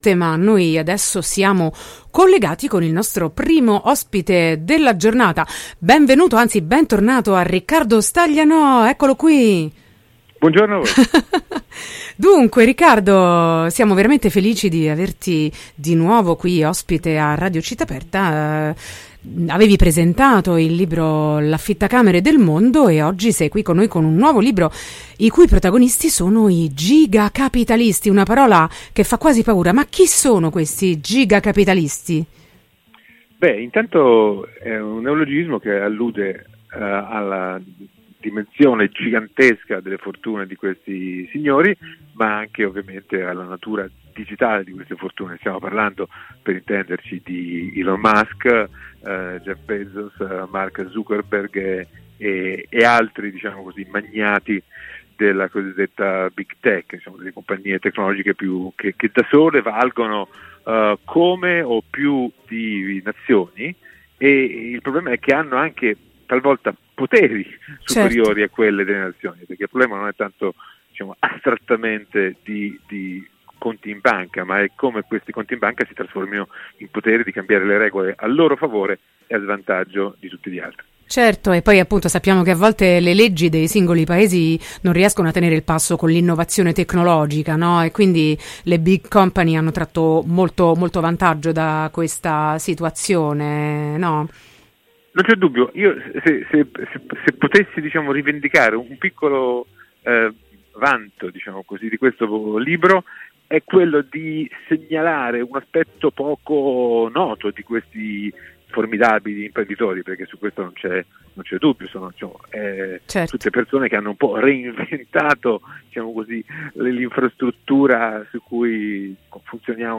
L’impero dei Gigacapitalisti – intervista a Riccardo Staglianò
Sono loro i Gigacapitalisti, protagonisti del nuovo libro del giornalista e scrittore Riccardo Staglianò, nostro ospite in collegamento.